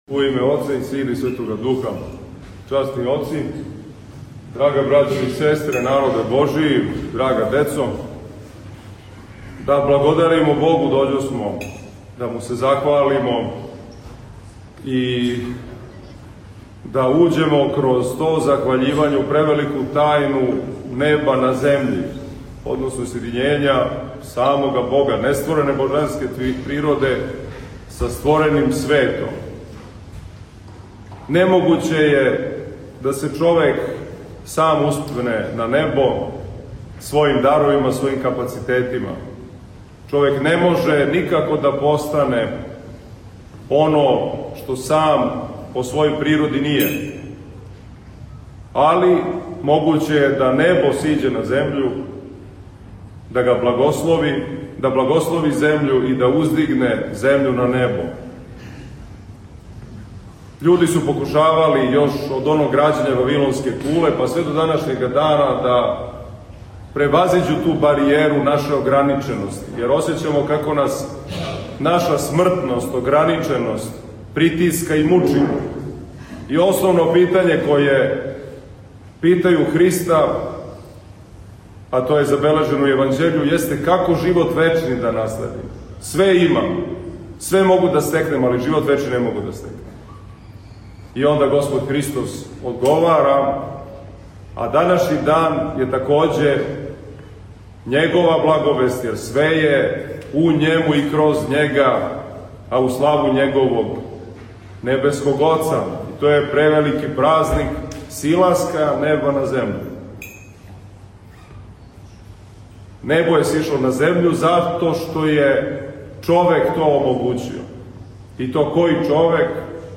У оквиру серијала „Са амвона“, доносимо звучни запис беседе коју је Његово Преосвештенство Епископ новобрдски г. Иларион, викар патријарха српског, изговорио на празник Рождества Пресвете Богородице, у недељу, 8/21. септембра 2025. године. Преосвећени владика је беседио на светој Литургији у храму Рождества Пресвете Богородице у Великој Моштаници надомак Београда.